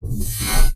drone1.wav